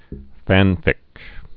(fănfĭk)